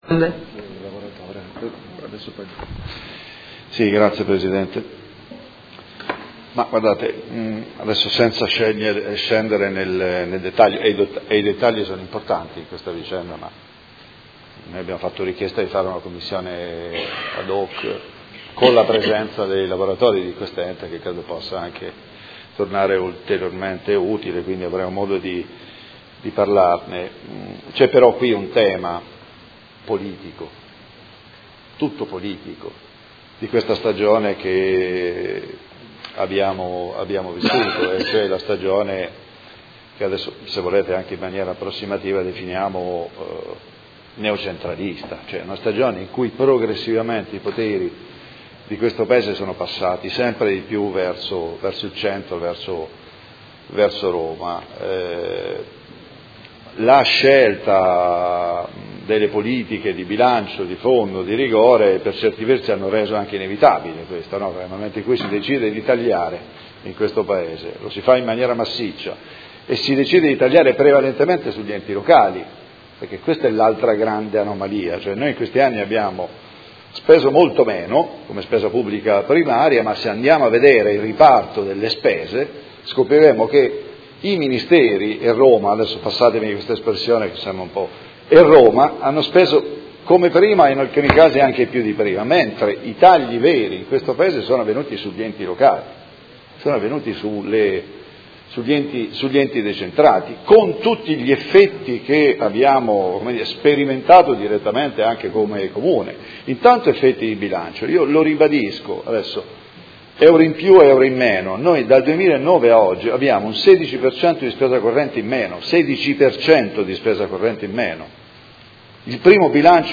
Seduta del 17/05/2018. Dibattito su interrogazione del Gruppo M5S avente per oggetto: Visita ispettiva MEF presso il Comune di Modena